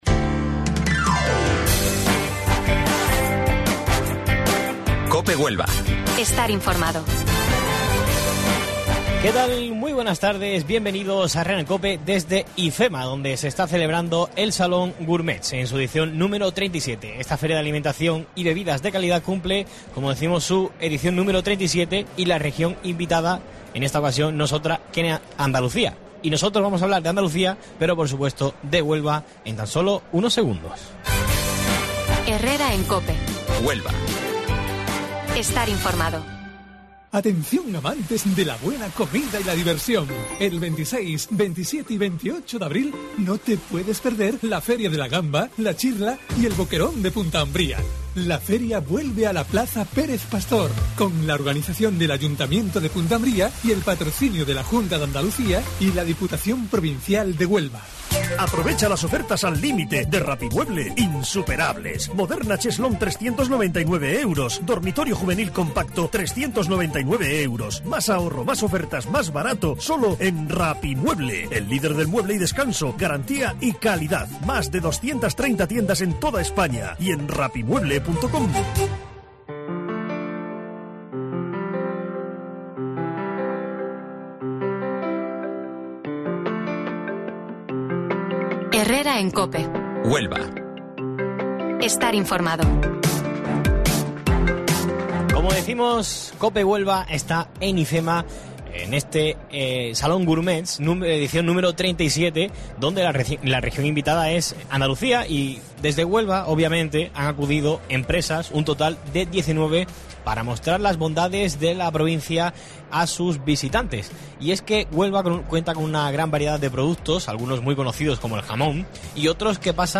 Desde IFEMA, con motivo del 37 Salón Goumerts, ponemos el foco en la gran variedad de productos gastronómicos que nos brinda la provincia de Huelva con algunos de los expositores.